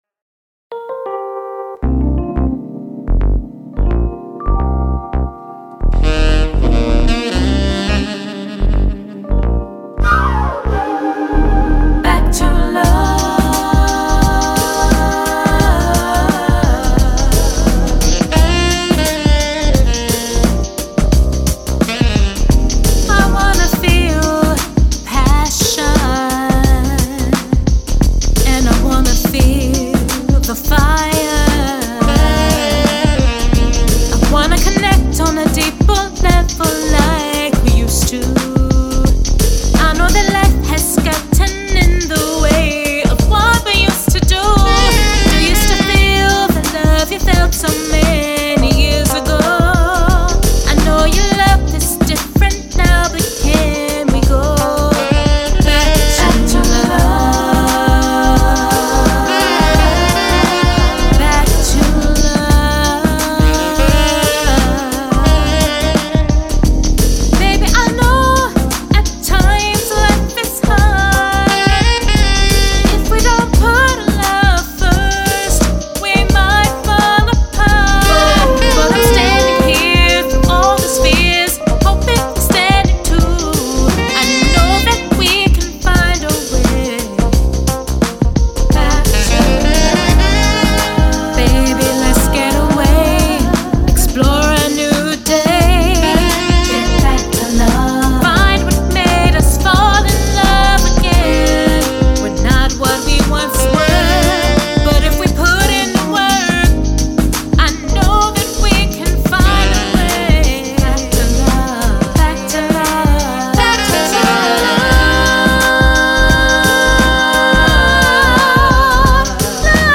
The angelic and melodic voice
Euphoria meets soul in my latest single
enchanting melodies
saxophone